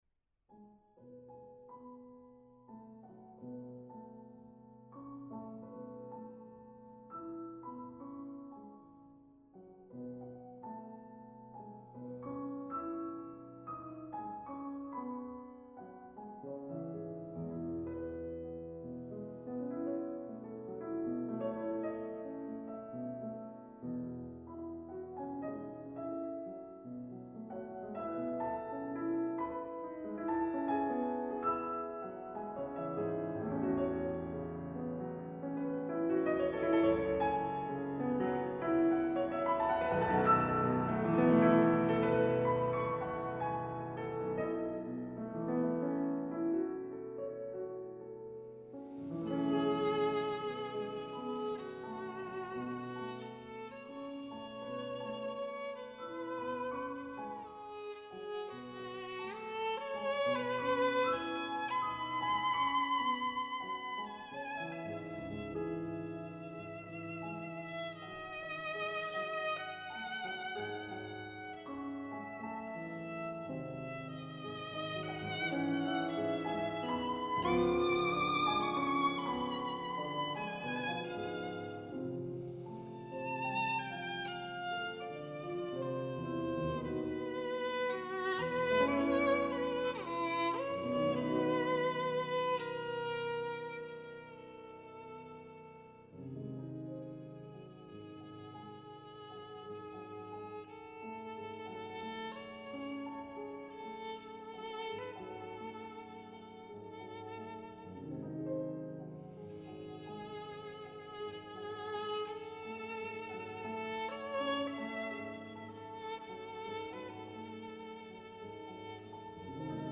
Sonata for violin and piano